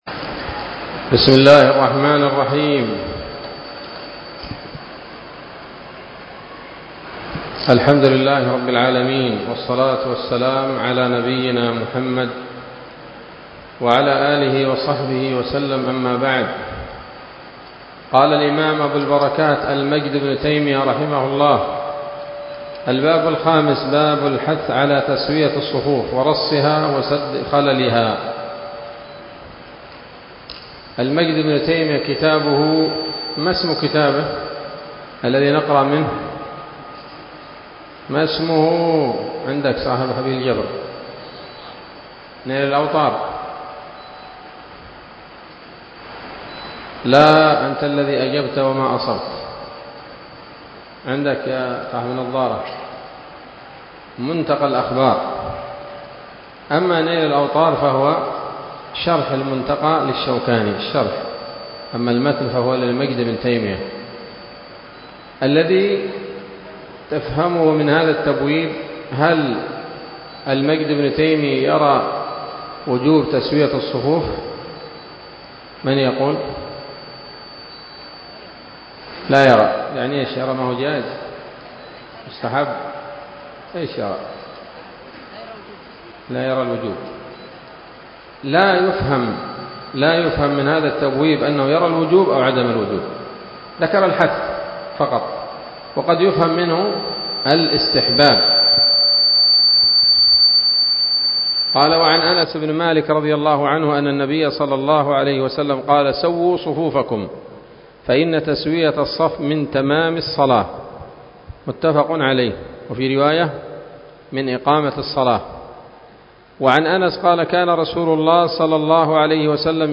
الدرس السادس من ‌‌‌‌‌‌أَبْوَاب مَوْقِف الْإِمَام وَالْمَأْمُوم وَأَحْكَام الصُّفُوف من نيل الأوطار